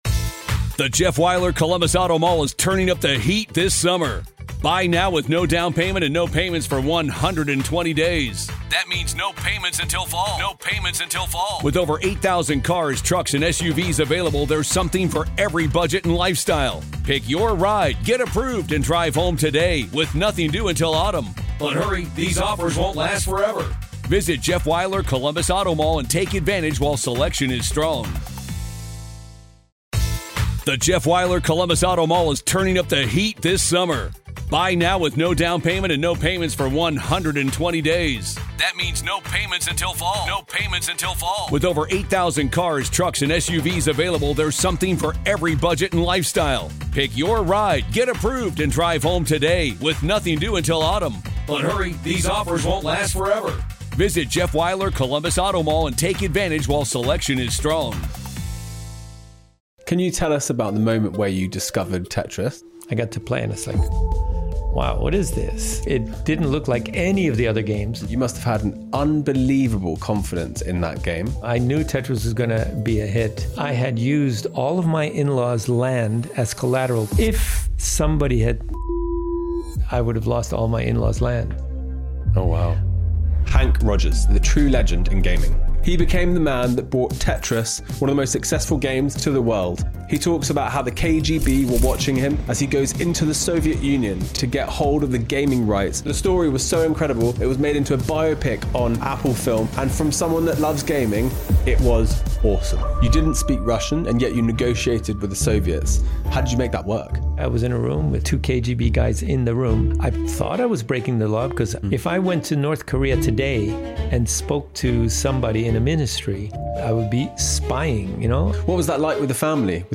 Today, I’m sitting down with Henk Rogers, the entrepreneur who brought Tetris to the world and outplayed billion-dollar corporations to secure the rights. His journey is a masterclass in risk-taking, negotiation, and reinvention.